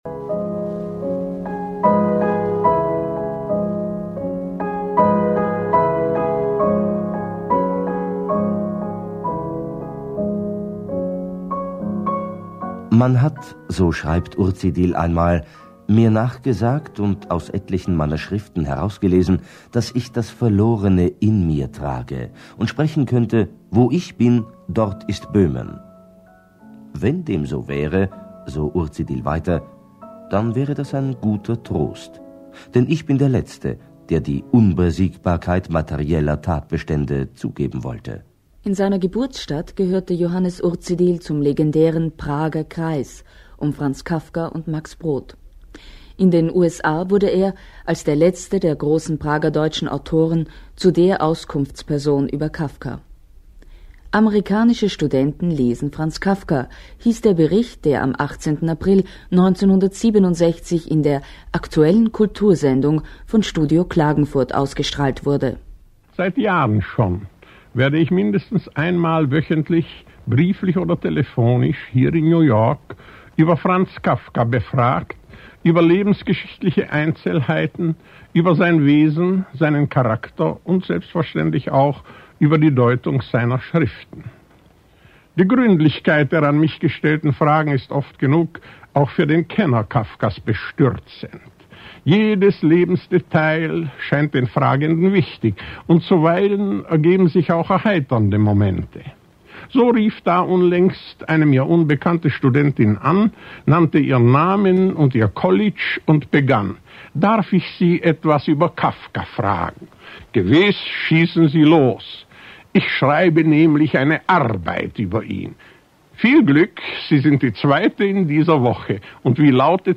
Tonaufnahme aus der diesem Buch beigefügten CD, wo Urzidil über Franz Kafka spricht.
Urzidil-ueber-Kafka.mp3